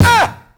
DBlends_Perc47.wav